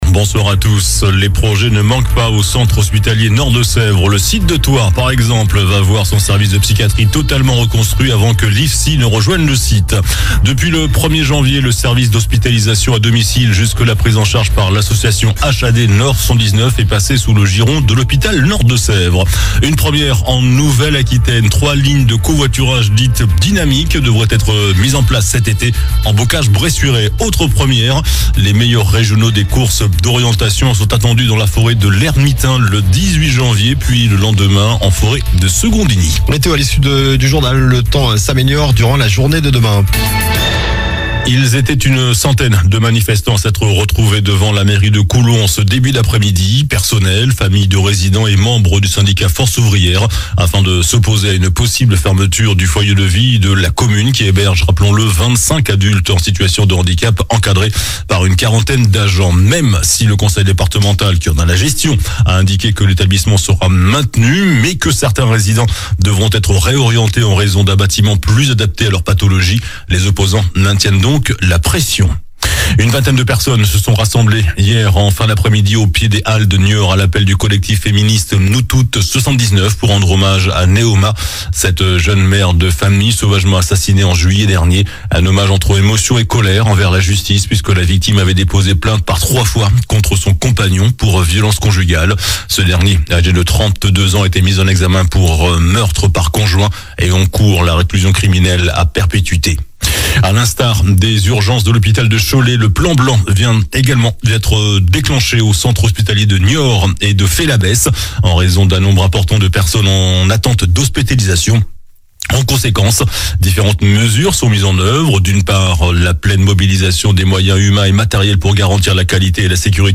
JOURNAL DU MERCREDI 08 JANVIER ( SOIR )